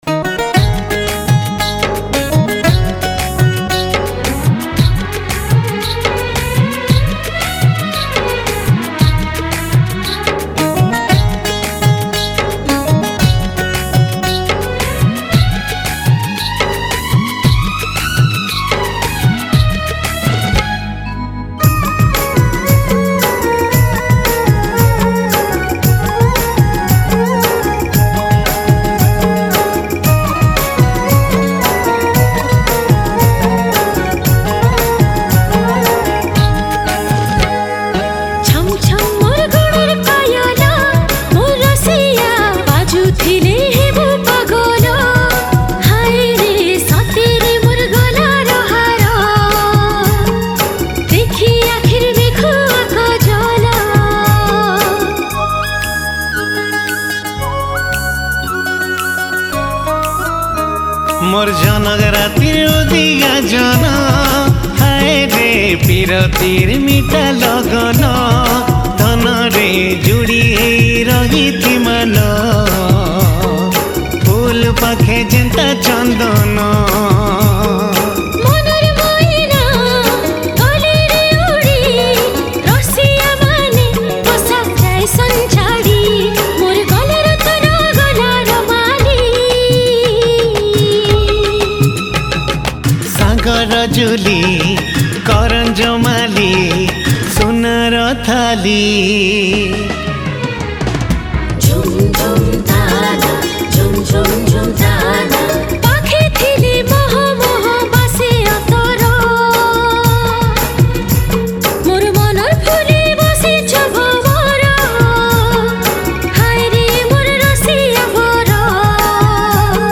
New Sambalpuri Song 2026